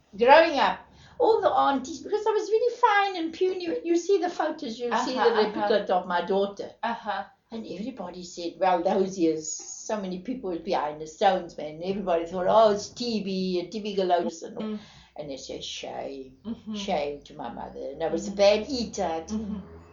South African woman in her 60s talks Afrikaans, about her childhood and growing up, having children, and having fun before having children.
• Female, White
South-African-Afrikaans-White-Female-60s-EJDD.mp3